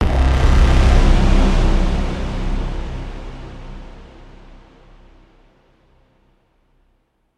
鼓声/咚咚声（电影预告片的跳场风格）。
描述：来自Hydrogen的鼓声（开源程序） 音调下降并在Audacity中添加了混响。
标签： 轰的一声 电影预告片 跳场景
声道立体声